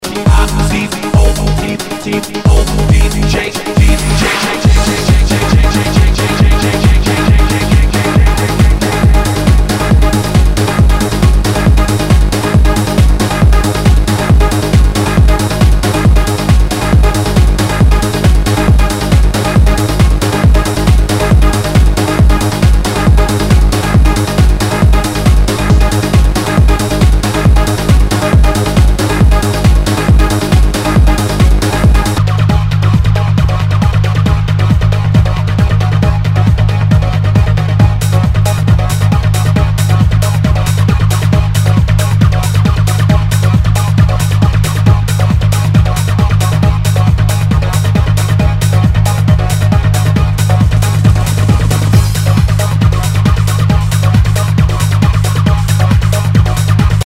HOUSE/TECHNO/ELECTRO
ナイス！ハード・ハウス / トランス！